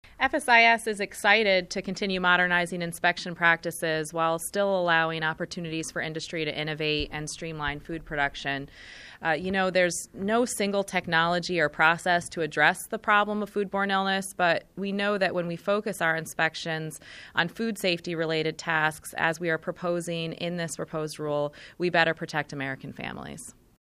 Listen to radio actualities from Acting Deputy Under Secretary Carmen Rottenberg: